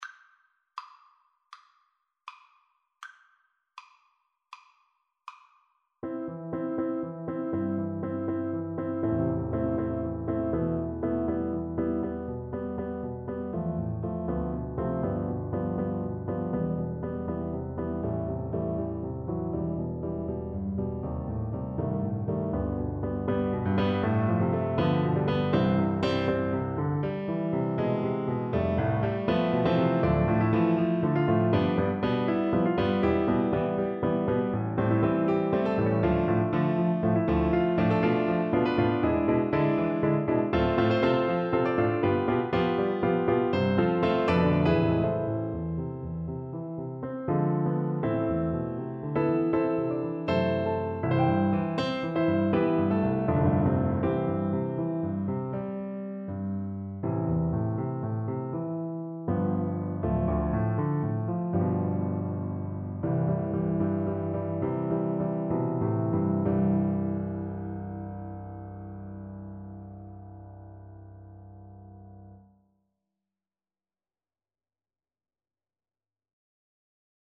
4/4 (View more 4/4 Music)
= 80 Moderato
Classical (View more Classical Viola Music)